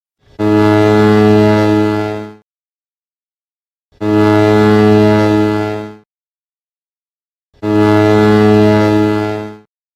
Nebelhorn klingelton kostenlos
Kategorien: Soundeffekte